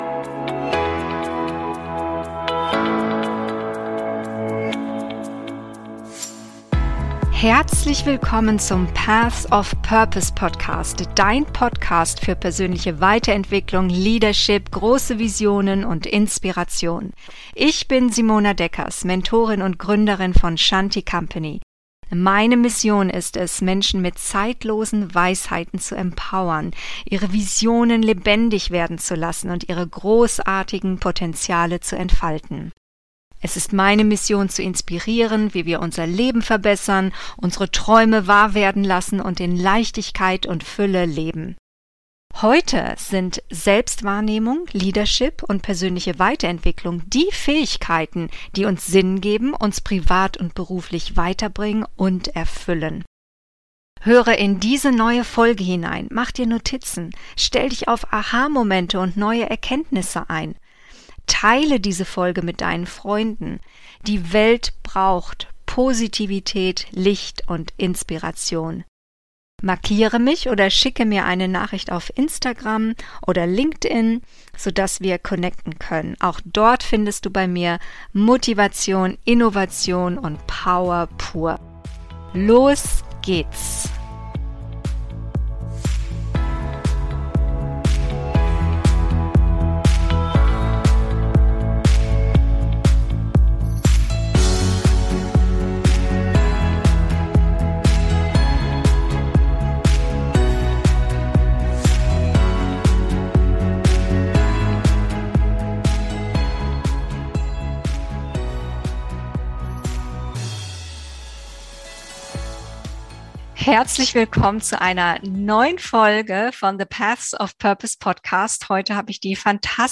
Better together oder Female Empowerment fürs Next Level! - Interview